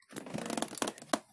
Crossbow_StringPull 01.wav